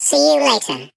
Sfx_tool_spypenguin_vo_exit_02.ogg